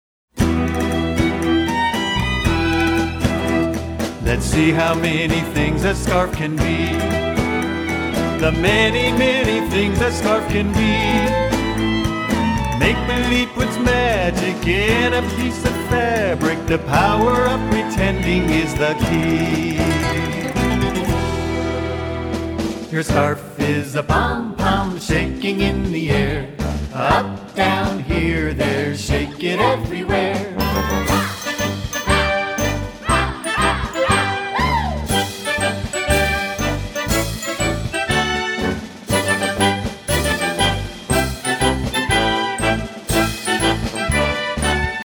song clip